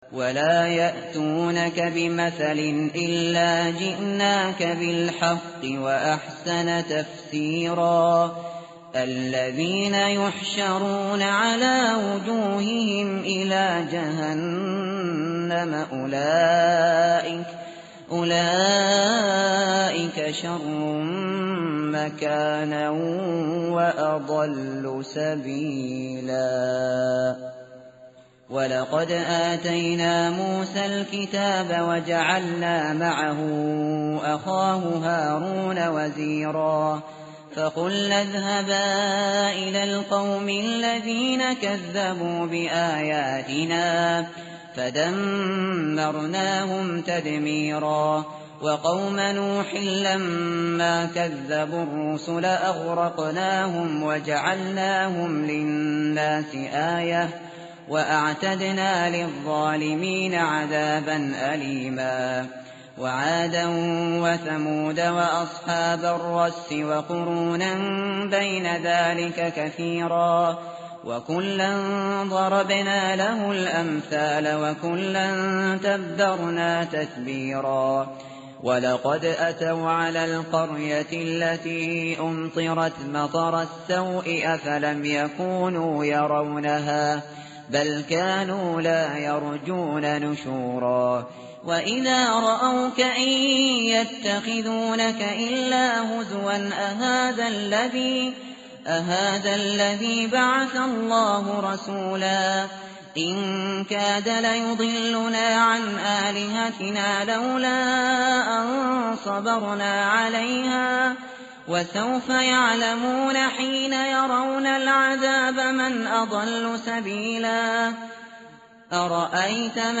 متن قرآن همراه باتلاوت قرآن و ترجمه
tartil_shateri_page_363.mp3